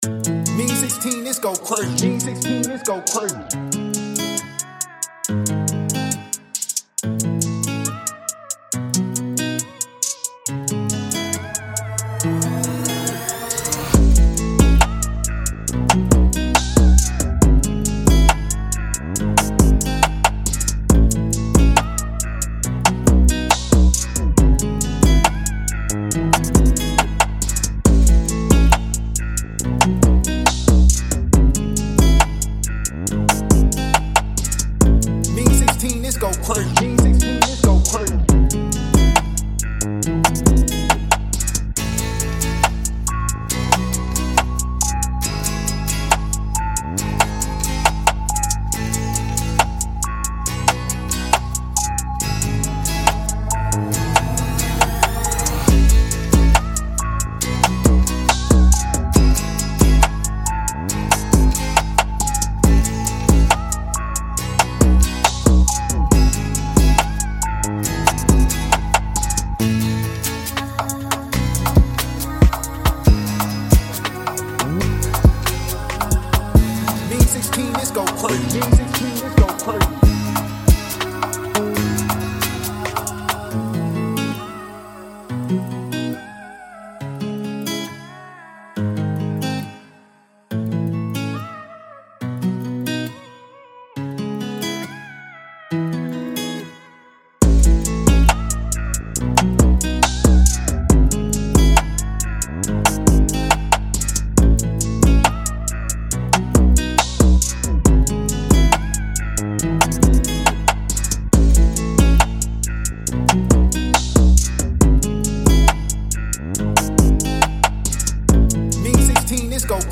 C#-Min 138-BPM